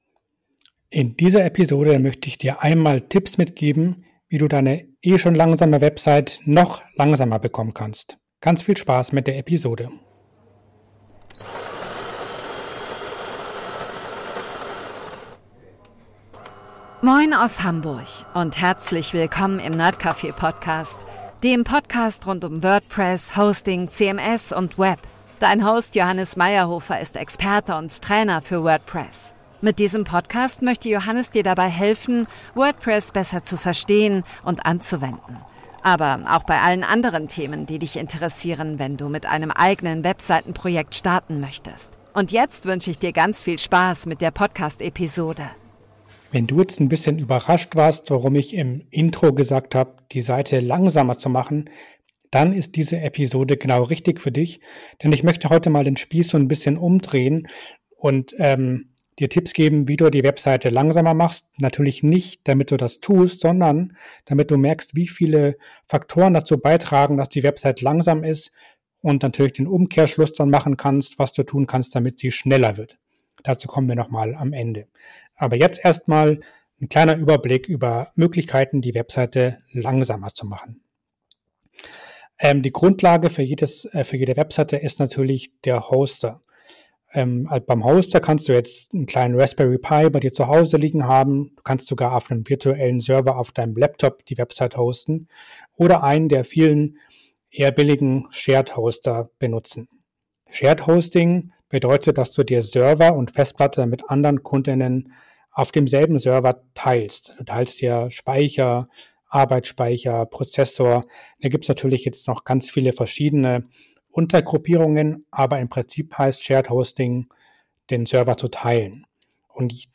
Der nerdcafe Podcast steht für ein offenes, vielfältiges und zugängliches Internet – verständlich erklärt, entspannt im Ton, aber mit Tiefgang.